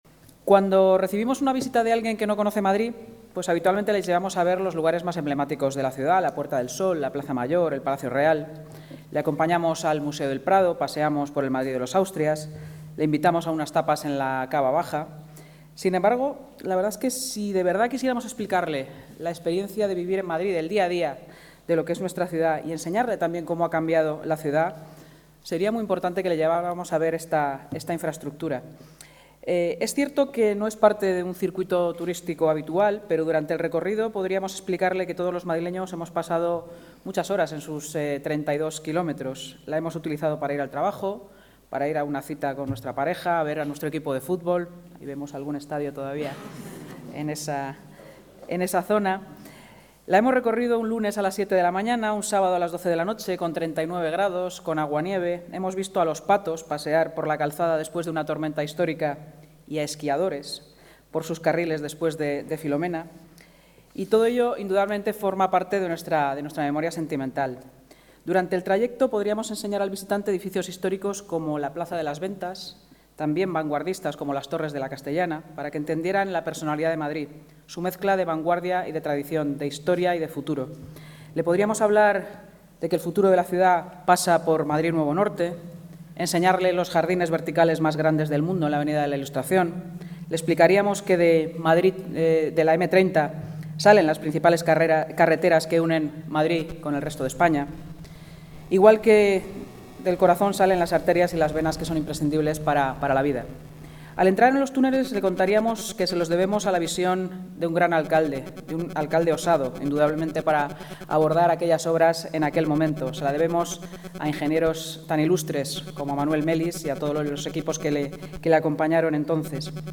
Nueva ventana:Intervención de la vicealcaldesa de Madrid, Inma Sanz
(AUDIO) INTERVENCIÓN VICEALCALDESA - PRESENTACIÓN LIBRO M30.mp3